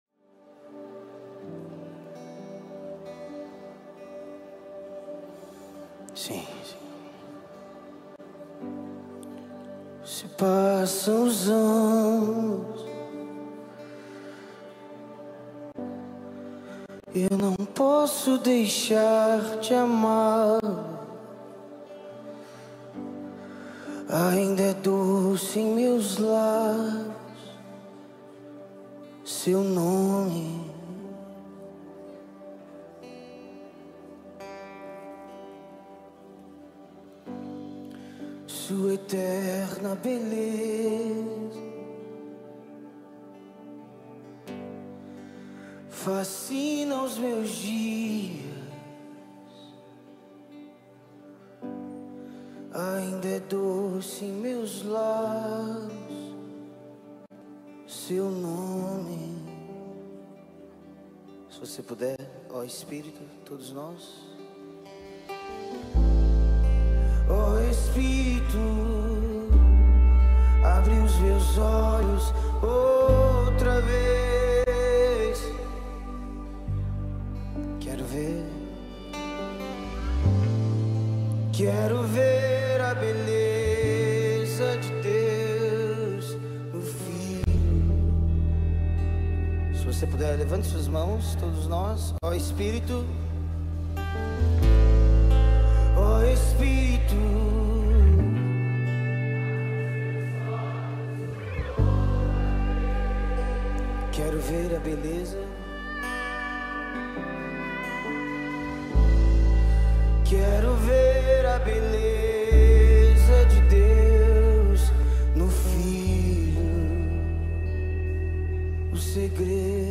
The dynamic Brazilian singer-songwriter
a mesmerising melody